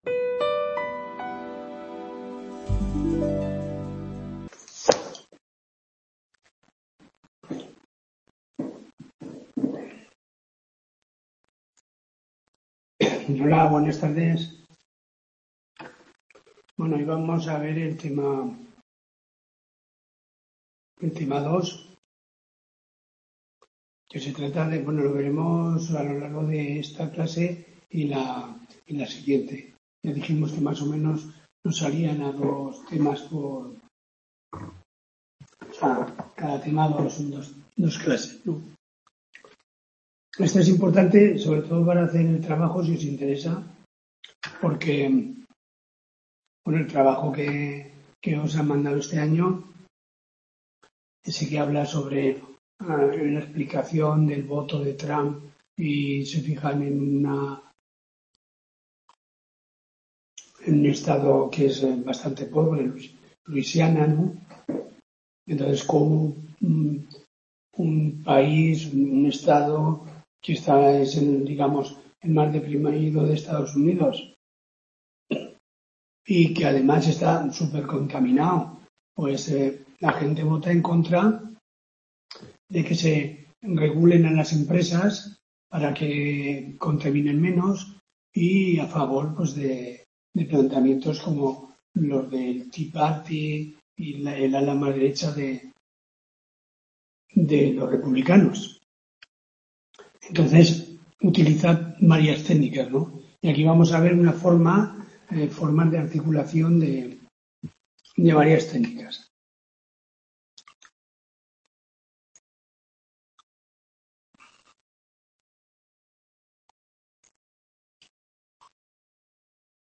Tutoría de 26/02/2025